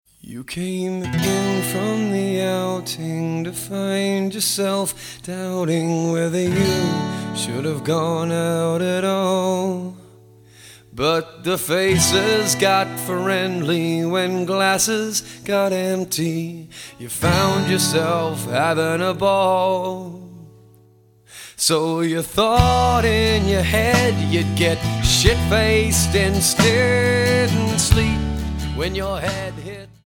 Alternative,Rock